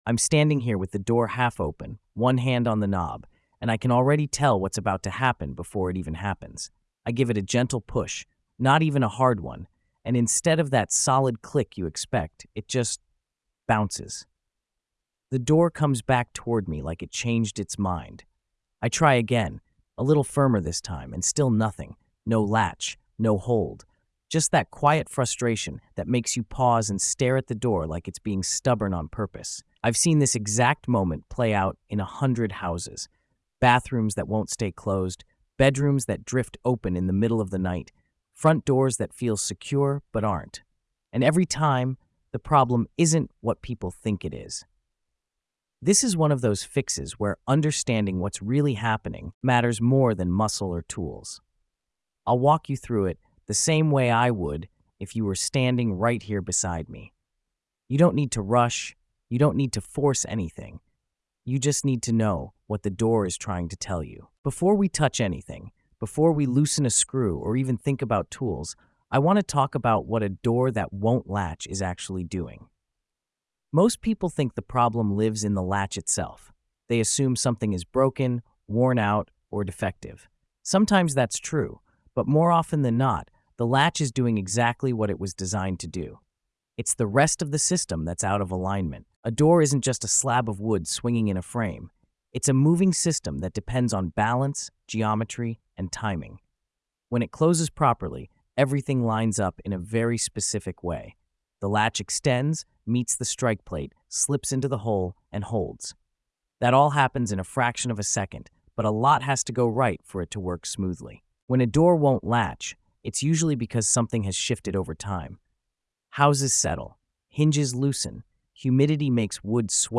Through calm, conversational teaching, the listener learns how doors shift over time, why latches miss their mark, and how small adjustments make a big difference. The episode emphasizes observation over strength, showing how to read movement, sound, and resistance before ever picking up a tool.